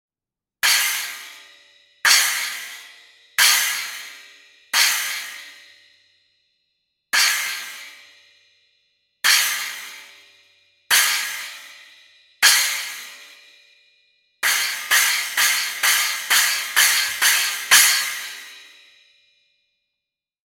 New for 2018, the Armor Series Effects provide great accents and new sounds for your set up.
Triple Stack Combo includes 6″ Armor Holey Splash, 8″ Armor Holey Splash and 10″ Armor Trash Splash